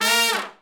Index of /90_sSampleCDs/Roland L-CD702/VOL-2/BRS_R&R Horns/BRS_R&R Falls